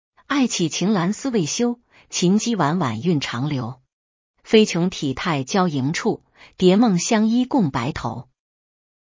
Azure-TTS-2024-12-01_02-31-07.mp3